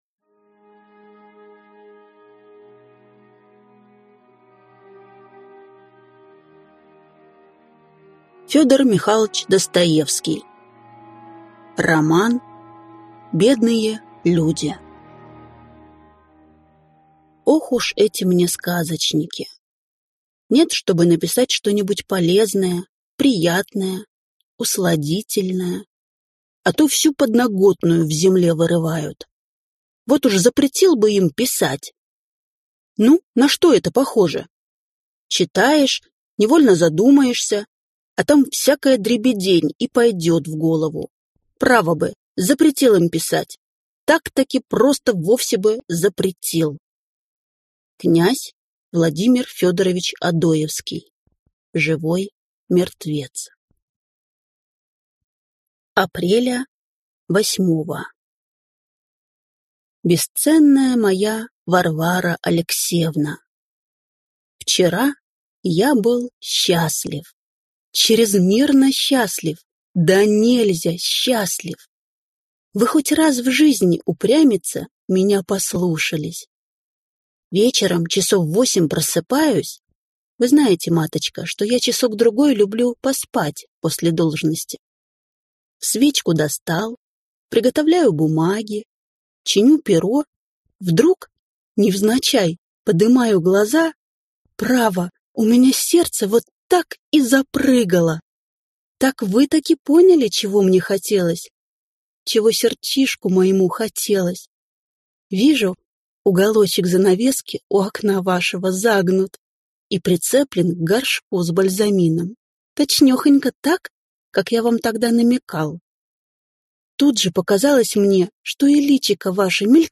Аудиокнига Бедные люди | Библиотека аудиокниг